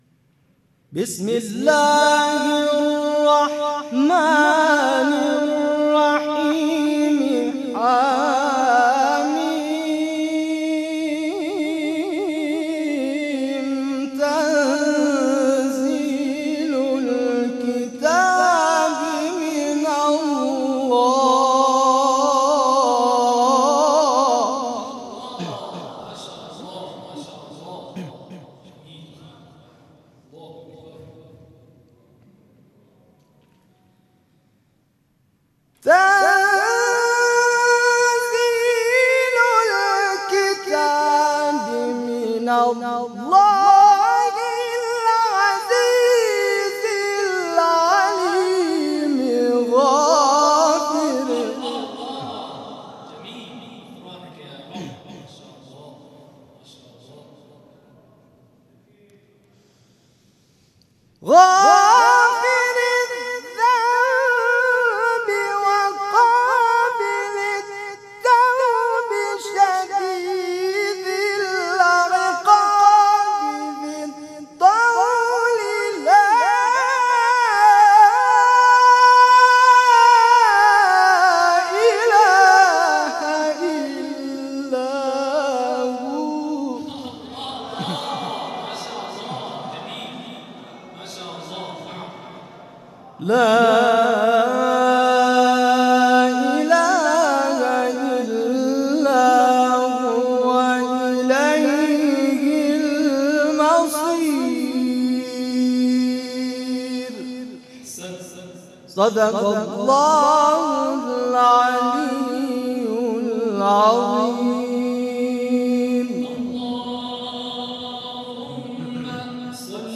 برچسب ها: تلاوت های مجلسی ، قاری بین المللی ، اردبیل